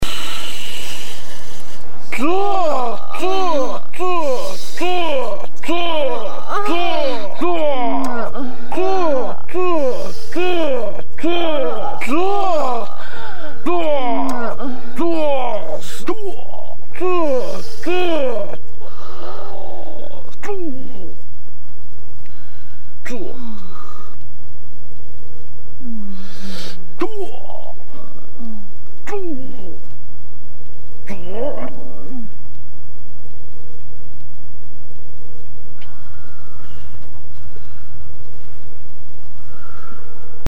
Vyslovuje se [CUA] a používá se buď ve zkrácené variantě ZU!, semidlouhé ZUa! nebo dlouhé ZUaaa!, přičemž každá varianta má svůj specifický význam a oblasti použití.
Jak můžete slyšet na audionahrávce, existují i další varianty tohoto pokřiku, např. [tů], [tu-ó], [ců], používají se sice minimálně, ale je dobré o nich vědět. Samotnému ZUa! jde ještě přidat na výrazu dlouhým a táhlým nadechováním před vlastním pokřikem - tak jako to předvedl chlápek v nahrávce - doporučuji důkladně prostudovat a naposlouchat.